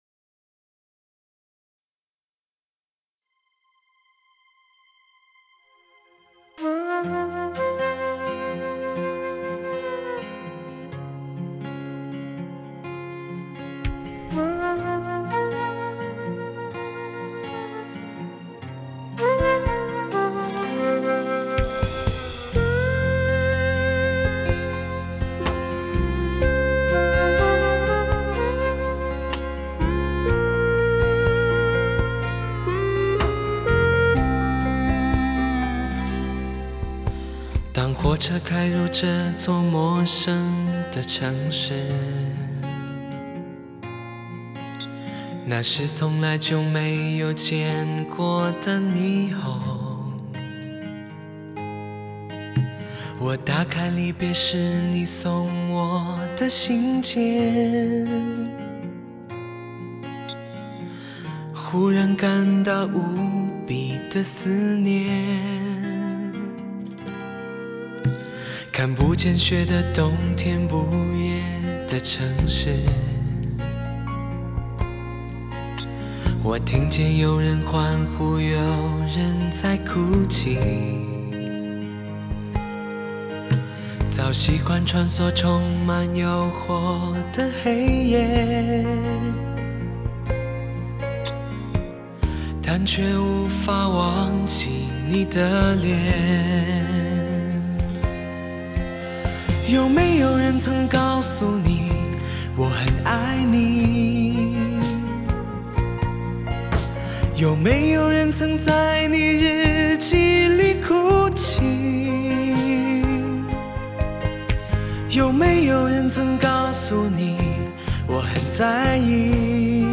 他那温润优雅的嗓音，气量充沛，音域广阔，纯熟温润的歌声。